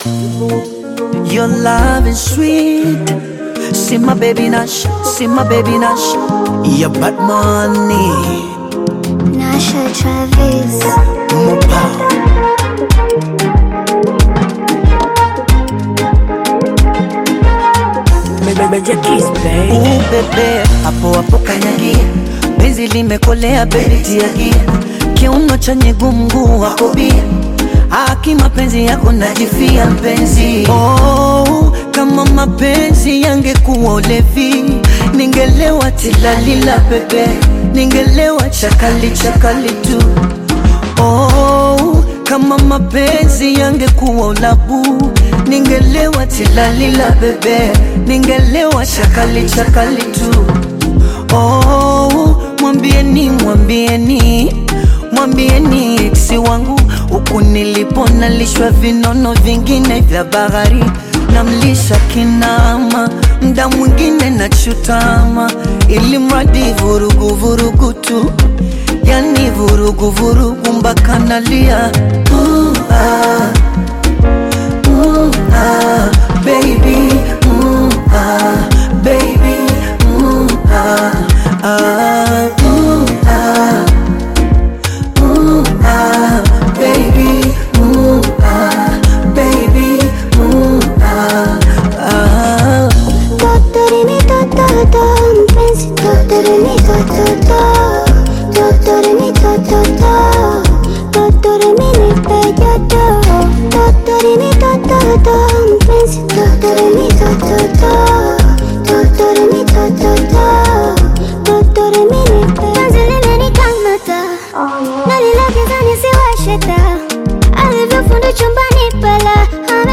Bongo Flava song